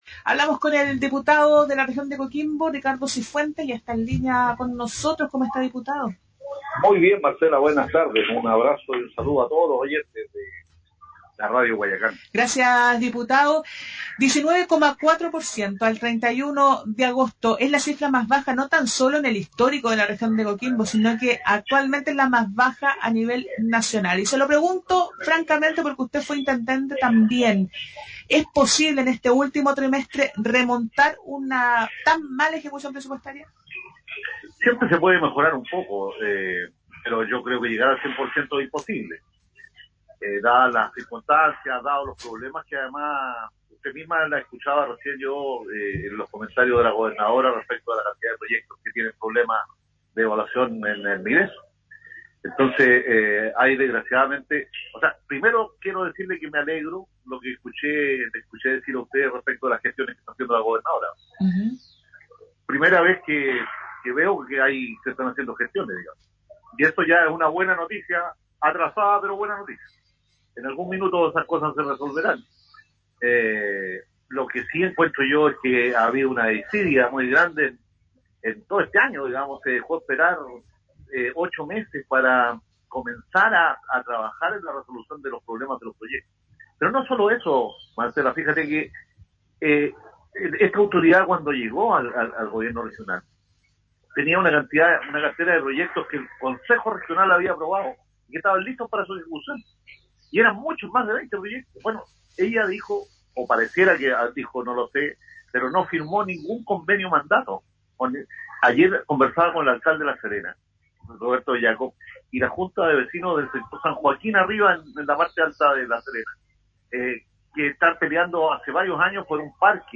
cifuentes-entrevista-completa-.mp3